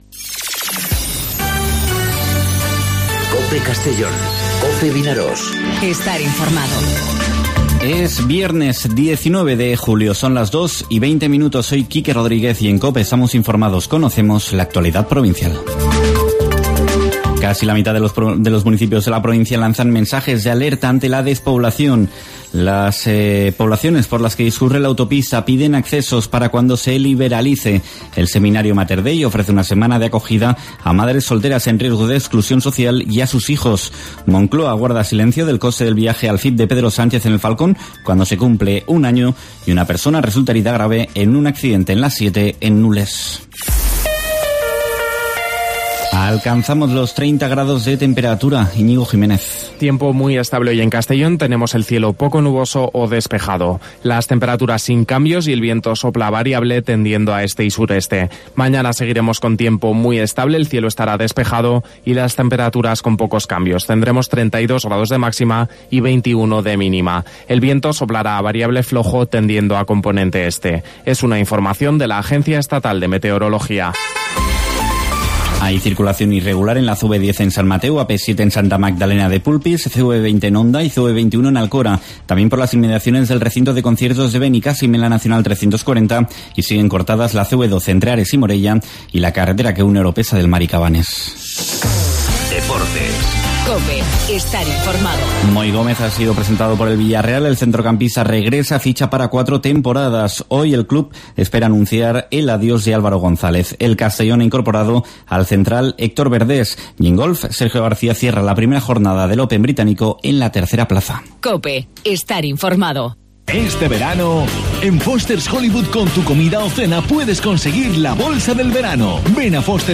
Informativo 'Mediodía COPE' en Castellón (19/07/2019)